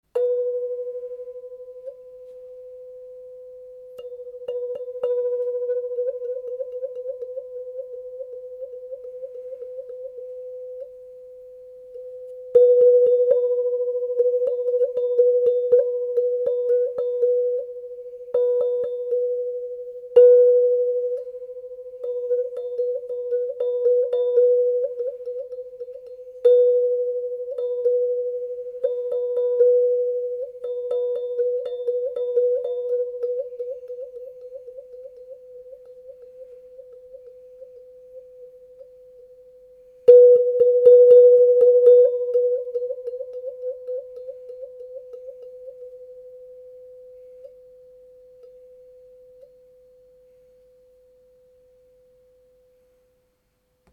WAH-WAH
« Cloche » cylindrique en aluminium au son puissant, doux et harmonieux, le sustain est très long. L’utilisation est très simple: il suffit de frapper le tube et de moduler le son en fermant et en ouvrant le trou à l’aide du pouce pour obtenir des sons « wah wah ».
Cet instrument peut être utilisé pour des effets rythmiques ou planants aussi bien que pour des voyages sonores.